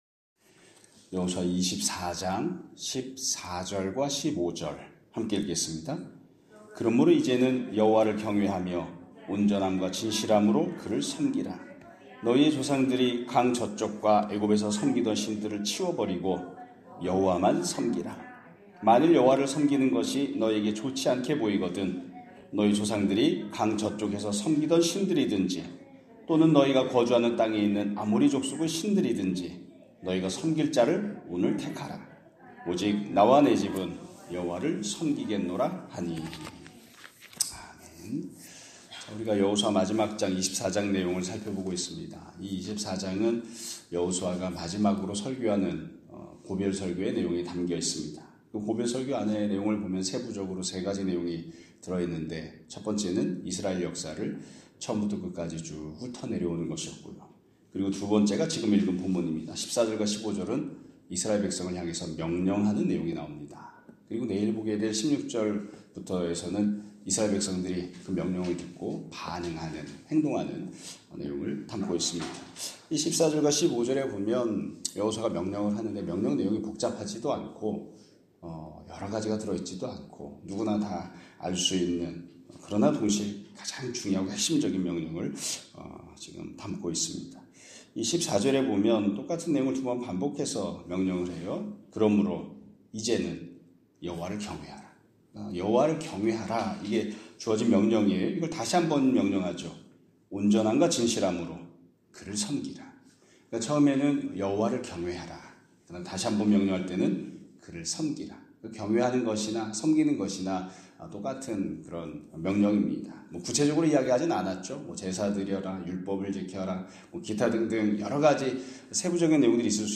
2025년 2월 26일(수요일) <아침예배> 설교입니다.